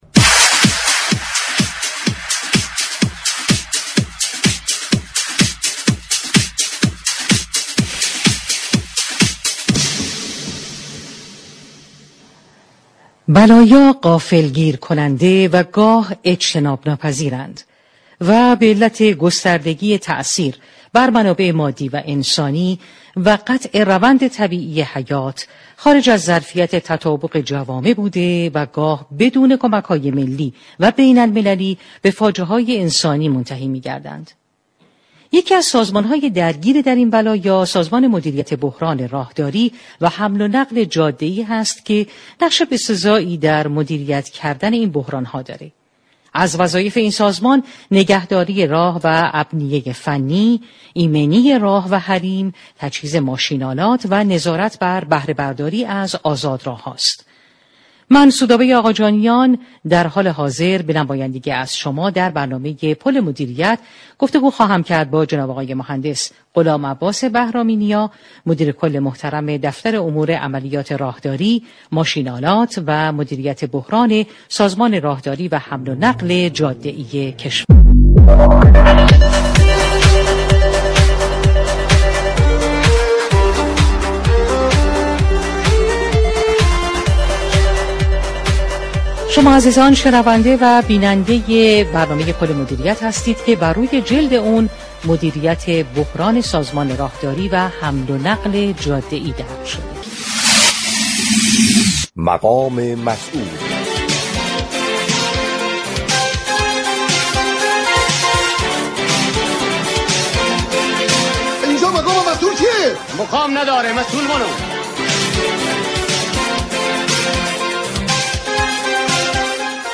به گزارش پایگاه اطلاع رسانی رادیو تهران، غلام عباس بهرامی نیا مدیركل دفتر امور عملیات راهداری، ماشین آلات و بحران سازمان حمل و نقل جاده ای كشور با حضور در استودیو پخش زنده رادیو تهران در روز یكشنبه 9 مرداد با برنامه پل مدیریت رادیو تهران گفت و گو كرد.